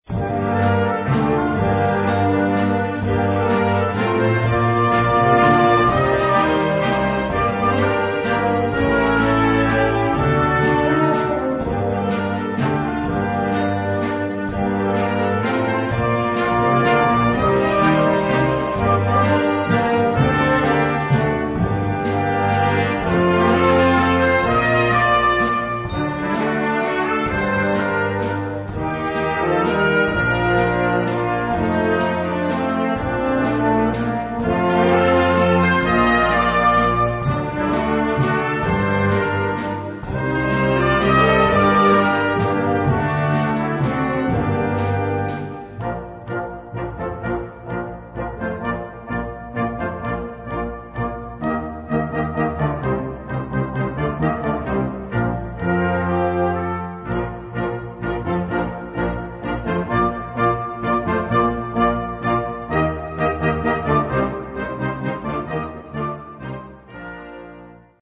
Unterkategorie Konzertmusik
Besetzung Ha (Blasorchester)